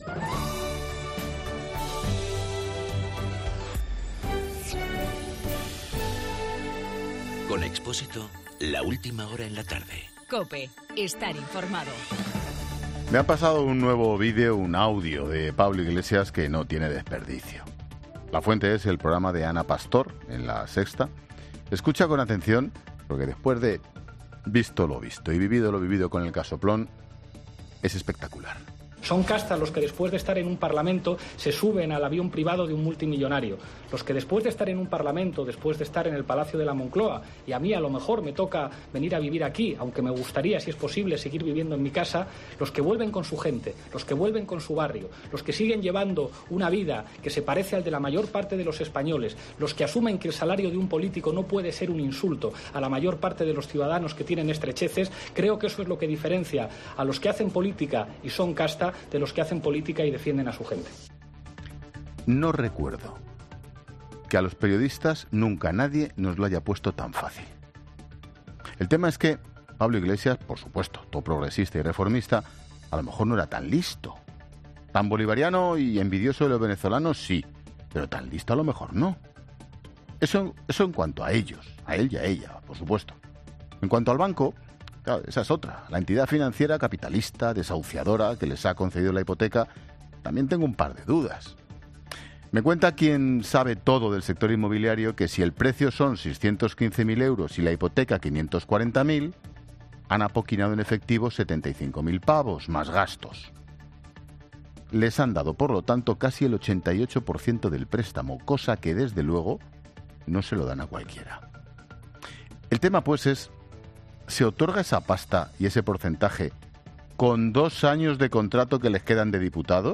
Monólogo de Expósito
El comentario de Ángel Expósito sobre Pablo Iglesias e Irene Montero.